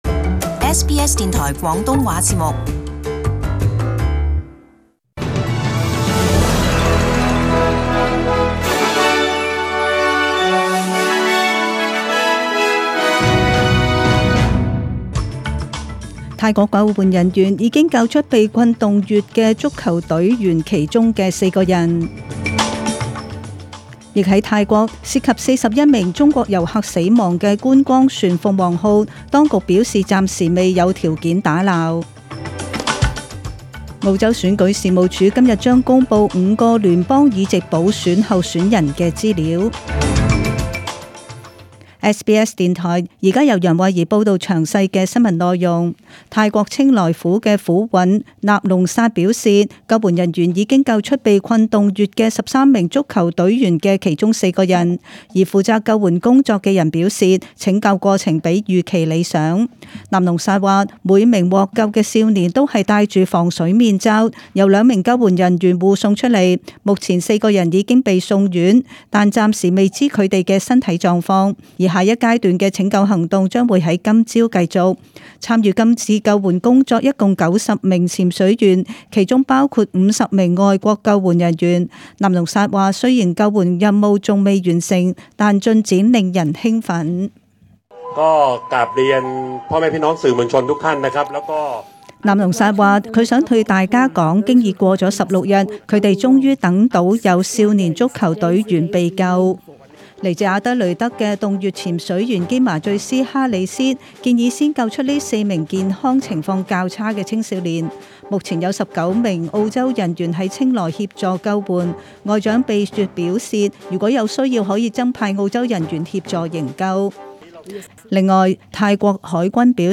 SBS中文新闻 （七月九日）
请收听本台为大家准备的详尽早晨新闻。